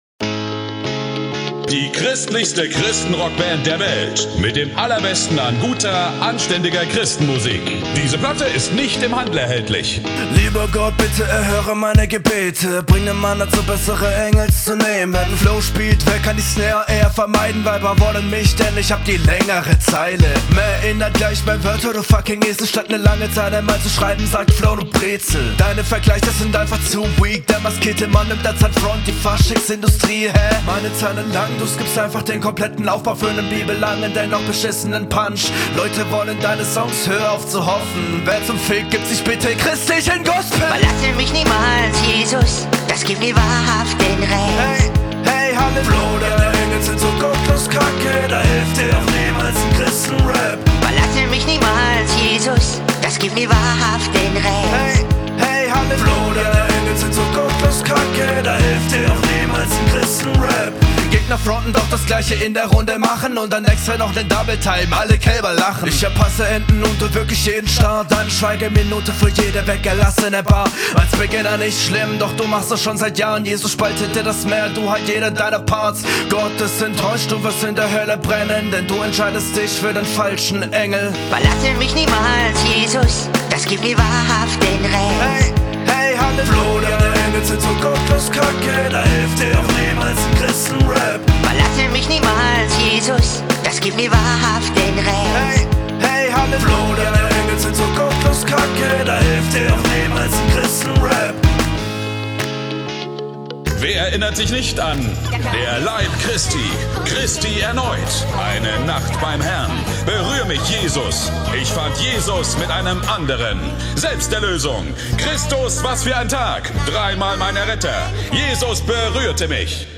Runde baut auf jeden Fall eine gute Atmosphäre auf und ist so ein guter Mix …